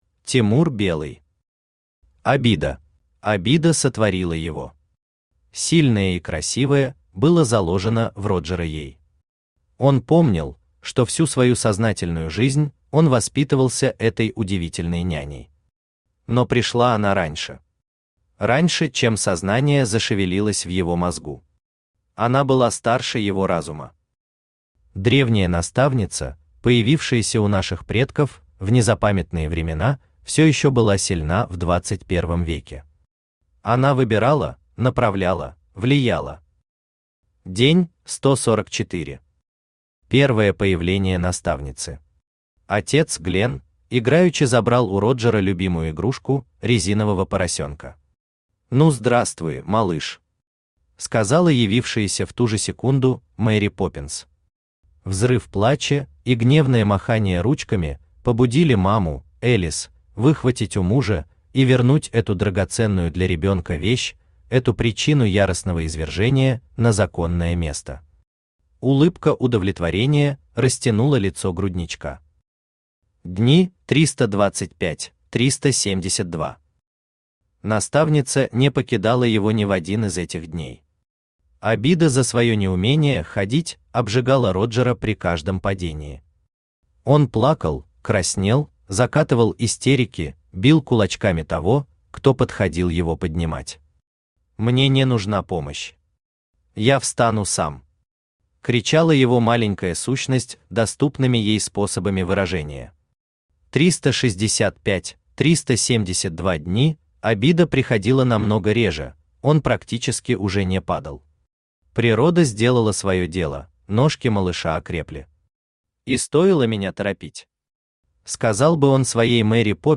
Аудиокнига Обида | Библиотека аудиокниг
Aудиокнига Обида Автор Тимур Белый Читает аудиокнигу Авточтец ЛитРес.